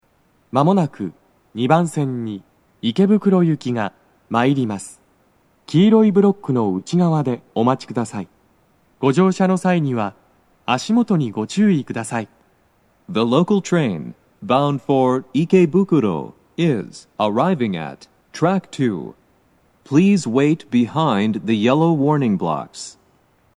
スピーカー種類 BOSE天井型
🎵接近放送
鳴動は、やや遅めです。
2番線 池袋方面 接近放送